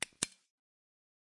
flashlight sound
flashlight-switch.ogg